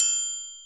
triangle.wav